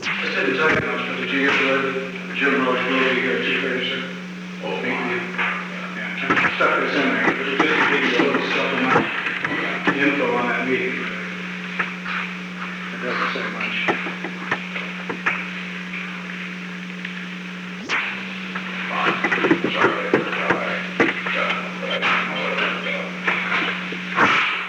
Recording Device: Oval Office
On May 13, 1971, President Richard M. Nixon and Alexander P. Butterfield met in the Oval Office of the White House at an unknown time between 12:50 pm and 1:00 pm. The Oval Office taping system captured this recording, which is known as Conversation 498-007 of the White House Tapes.